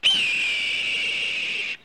*red tailed hawk sound*
Team Sky *screech*!